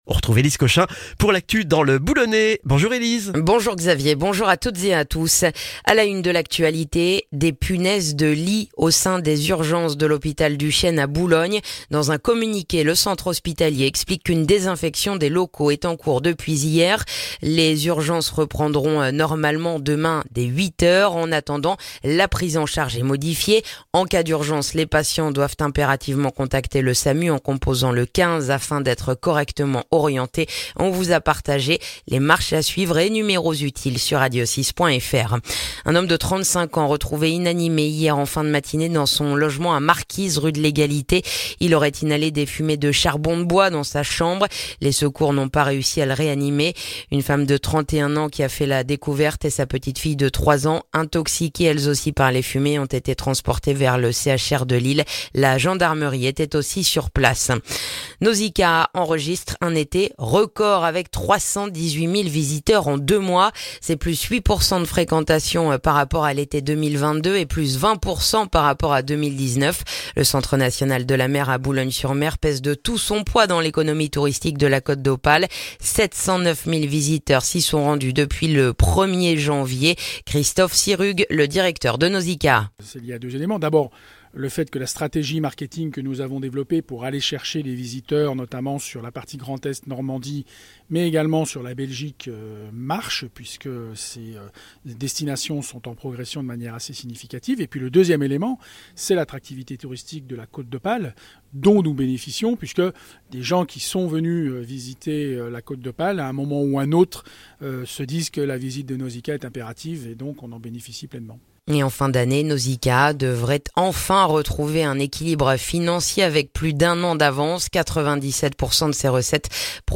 Le journal du jeudi 7 septembre dans le boulonnais